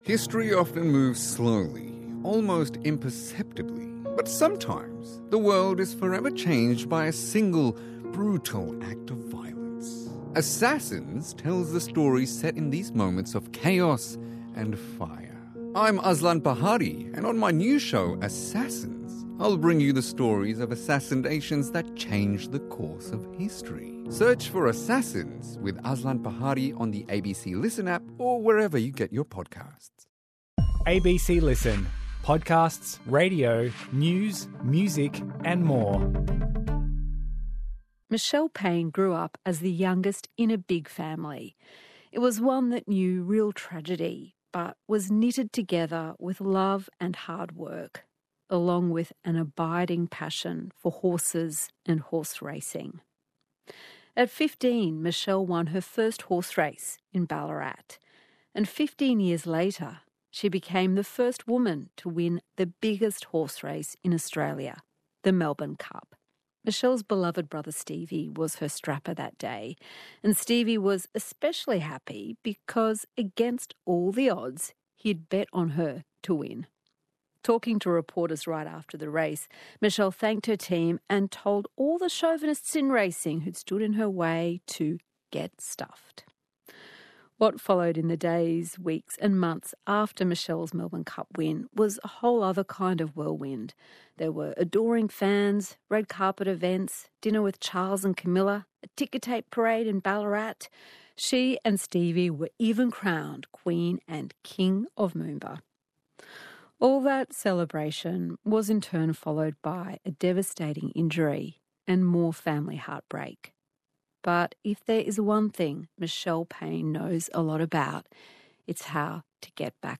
Hosted by Richard Fidler and Sarah Kanowski, Conversations is the ABC's most popular long-form interview program.